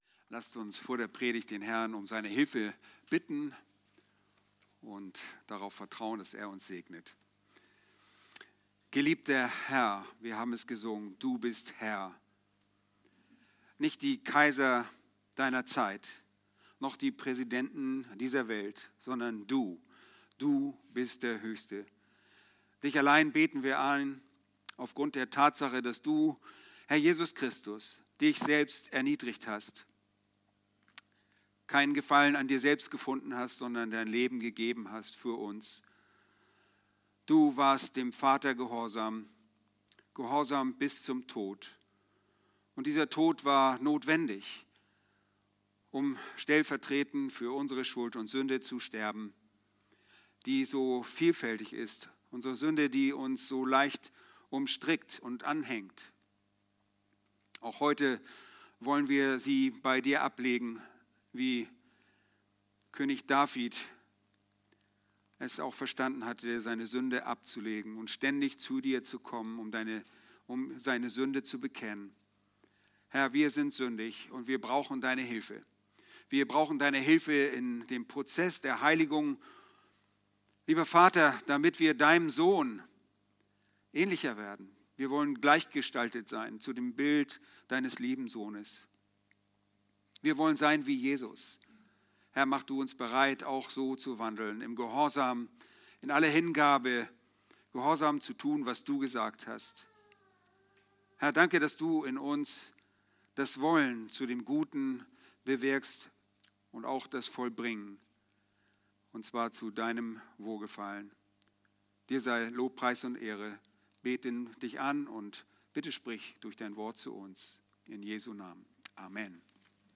Aktuelle Predigten - Bibelgemeinde Barnim